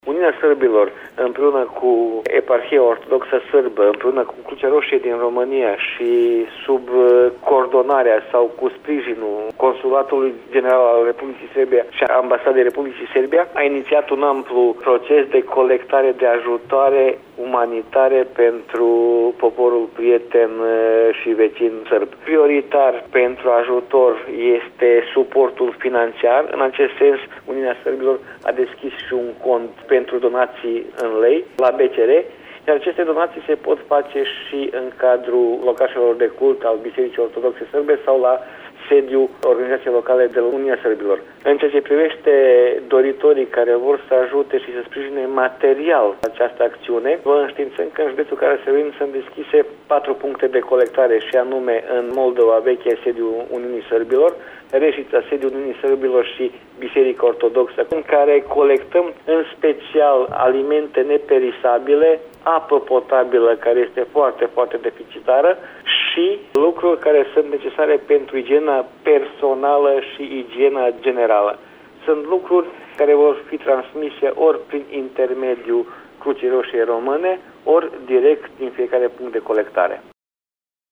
Mai multe amănunte despre implicarea în acţiunile umanitare ale bănăţenilor am aflat de la Ognian Crstic – preşedintele Uniunii Sârbilor din România: